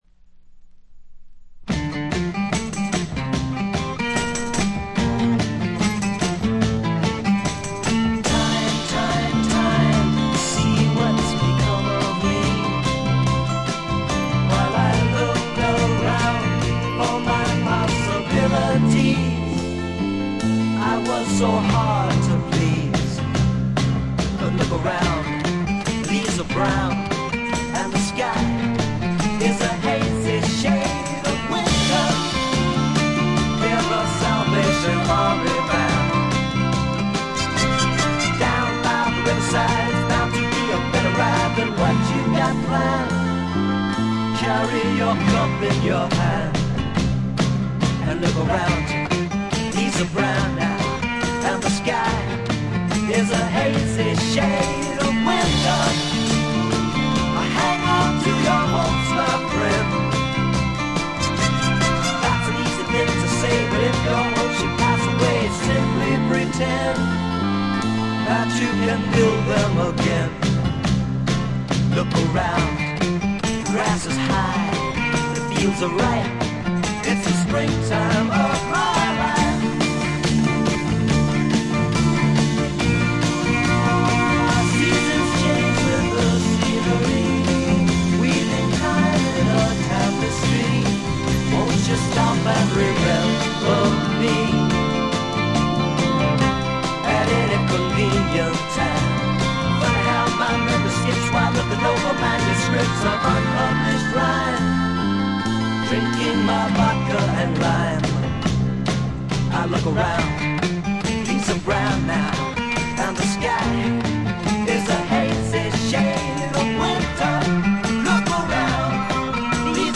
静音部で軽微なチリプチ少々。
試聴曲は現品からの取り込み音源です。